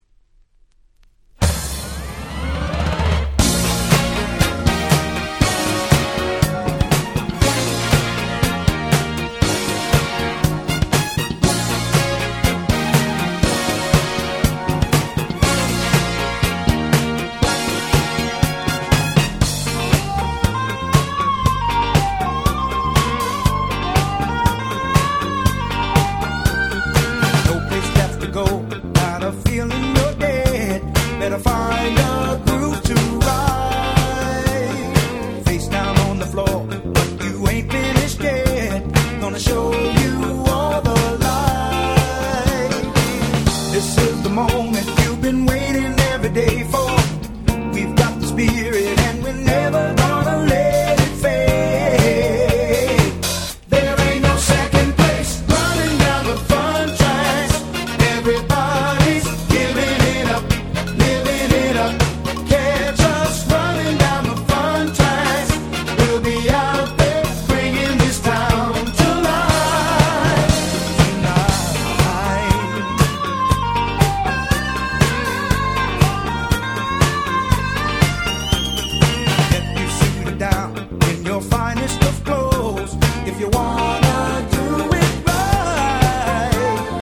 82' Very Nice Disco / Boogie !!
ディスコブギー